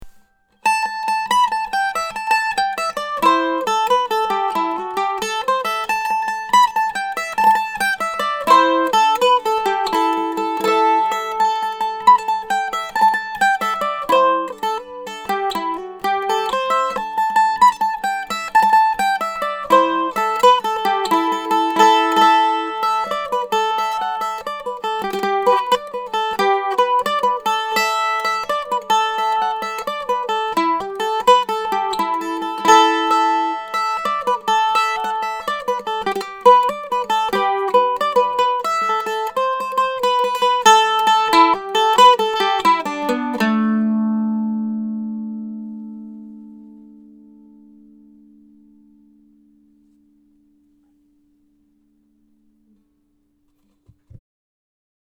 Spruce—blackwood mandolin - Sold
Hand carved Master quality European spruce top ('Moonwood')
Hand carved blackwood back, sides and neck